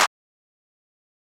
EN - Sizzle & Metro (Clap).wav